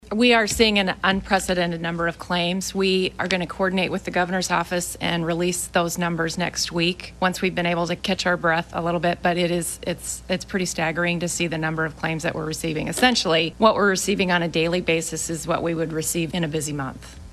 Gov. Kim Reynolds hosted another press conference this (Friday) afternoon and announced one additional case of COVID-19 had been identified in Iowa.
Friday-Presser-2.mp3